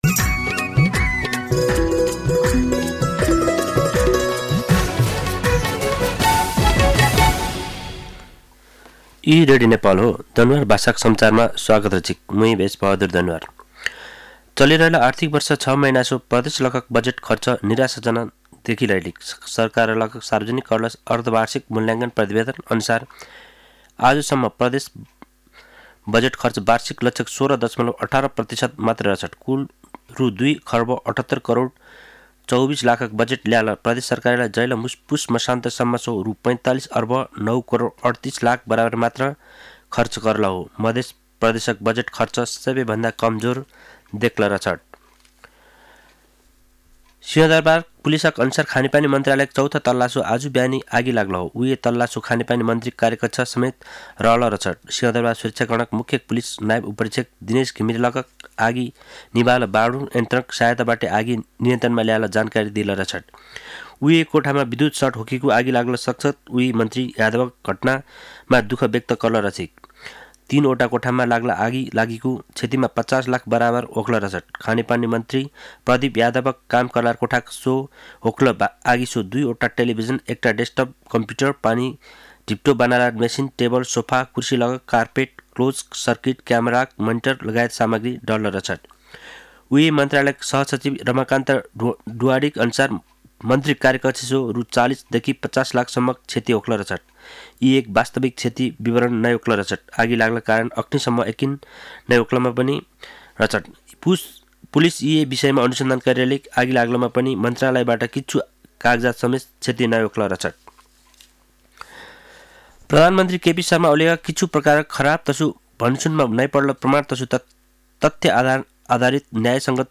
दनुवार भाषामा समाचार : २७ माघ , २०८१